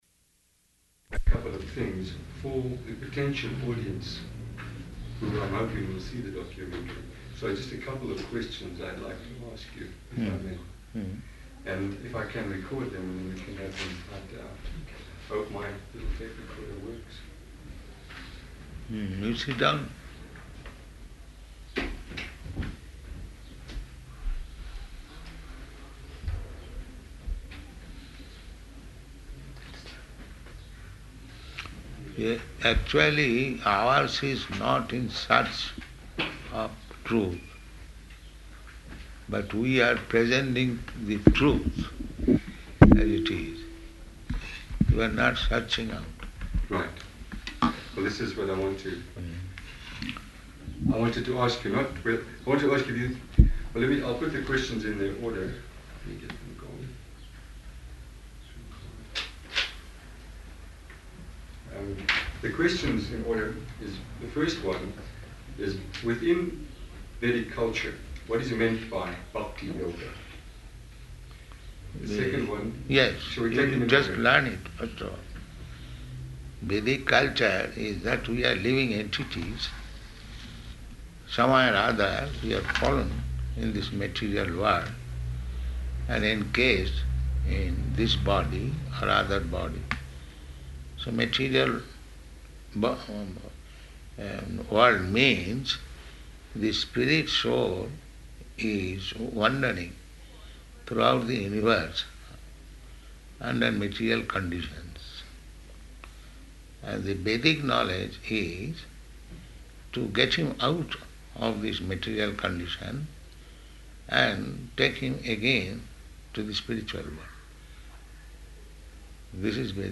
Room Conversation with Documentary Maker
Type: Conversation
Location: Johannesburg